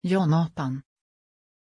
Pronunciación de Joonatan
pronunciation-joonatan-sv.mp3